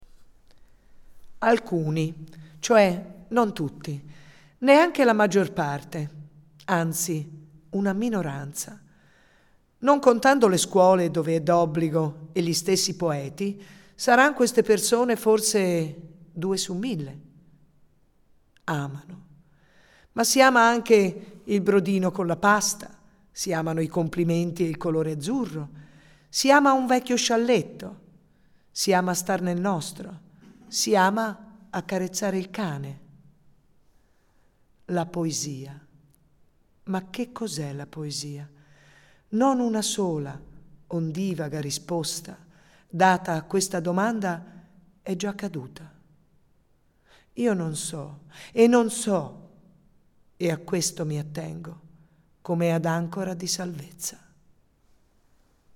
dallo spettacolo del 10 Luglio 2015
Nell’ascolto della recitazione degli attori, diventa esplicita anche un’altra caratteristica comune a molte delle sue poesie, cioè la loro teatralità intrinseca, che forse deriva proprio dall’immediatezza del loro stile: questo permette di interpretarle dando voce e vita direttamente agli stessi protagonisti delle poesie.